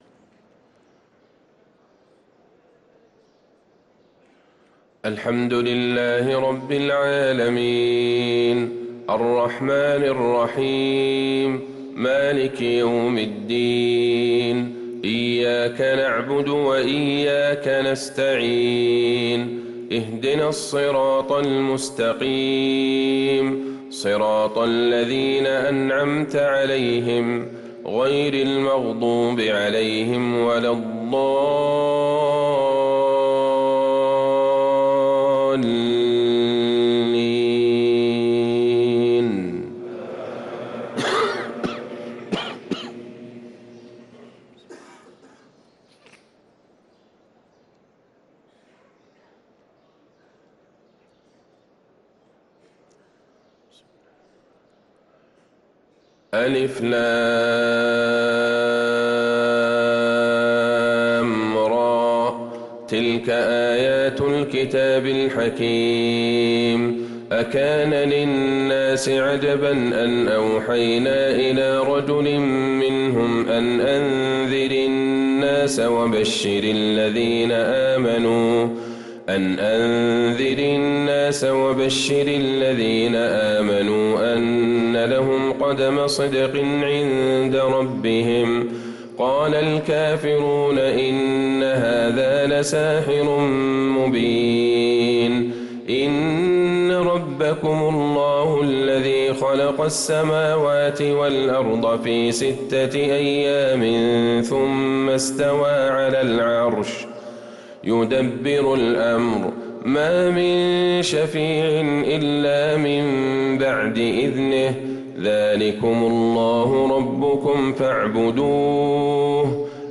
صلاة الفجر للقارئ عبدالله البعيجان 11 رجب 1445 هـ